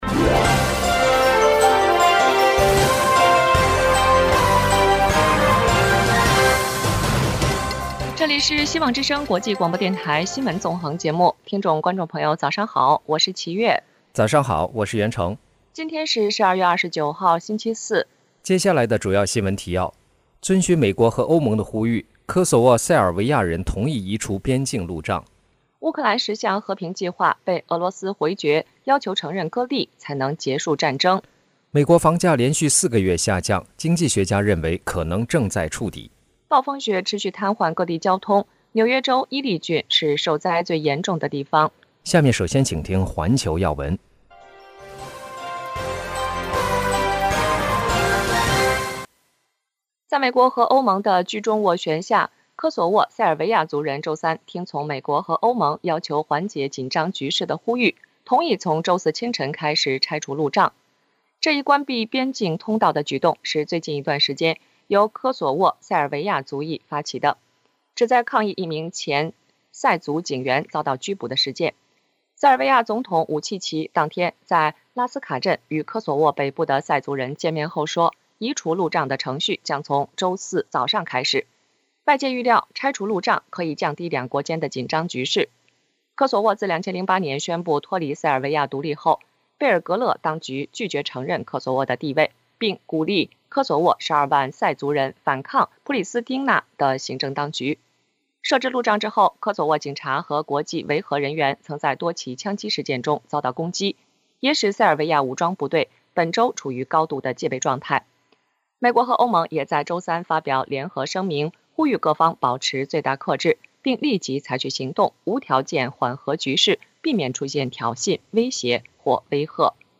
新聞提要（上半場） 環球新聞 1、遵循美